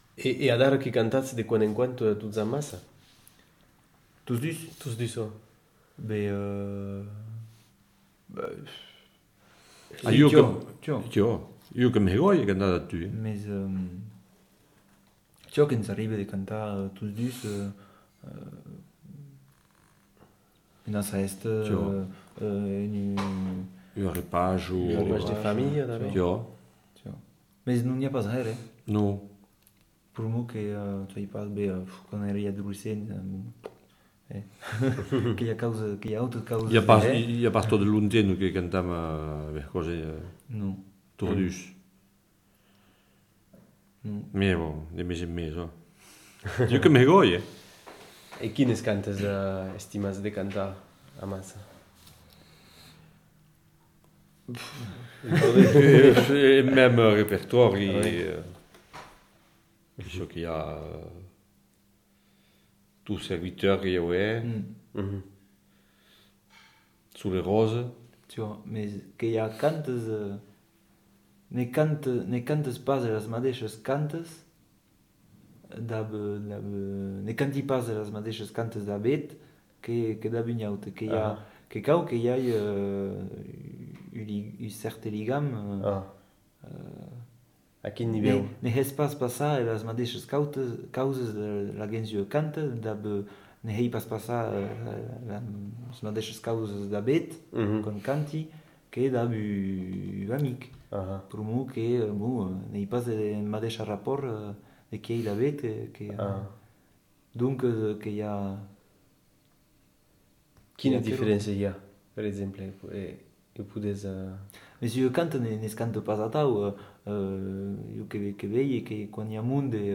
Aire culturelle : Bigorre
Genre : récit de vie